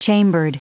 Prononciation du mot chambered en anglais (fichier audio)
Prononciation du mot : chambered